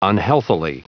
Prononciation du mot unhealthily en anglais (fichier audio)
Prononciation du mot : unhealthily
unhealthily.wav